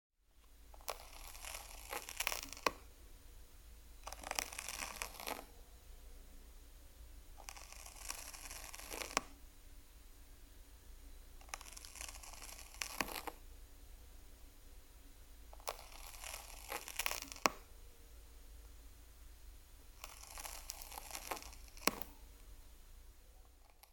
Звуки улитки
Звук грызущей дикой улитки